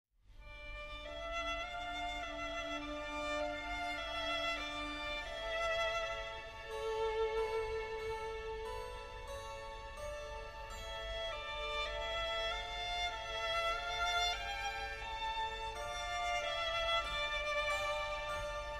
Правда, мелодия была грустная, и Дуня подумала, что это, наверное, кто-то плачет, и пошла на звук этой мелодии.
скрипка-плачет-20с.mp3